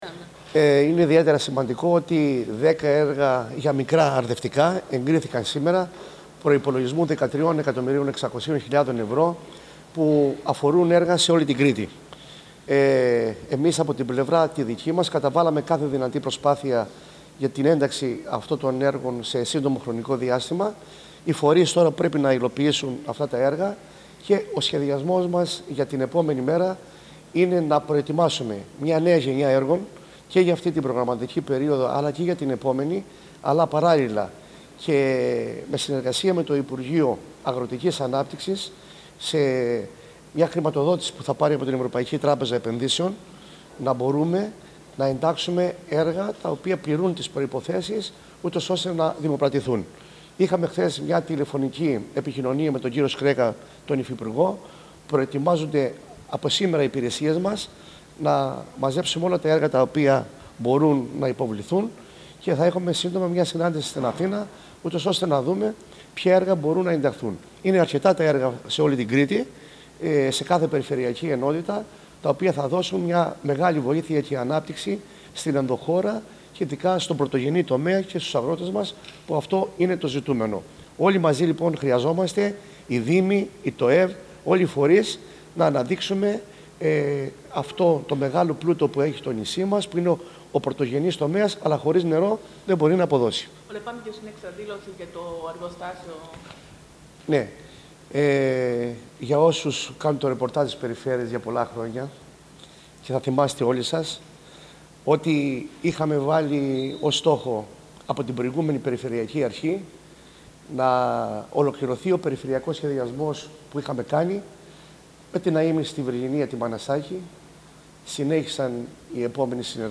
Ακούστε εδώ ολόκληρες τις δηλώσεις του Περιφερειάρχη Κρήτης Σταύρου Αρναουτάκη: